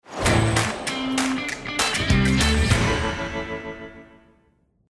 Een bumper is een kort stukje muziek wat je bijvoorbeeld tussen twee items kunt afspelen, om het programmaonderdeel een duidelijk begin en een eind te geven. Hieronder een voorbeeld van een eenvoudige korte bumper.
Bumper
Bumper.mp3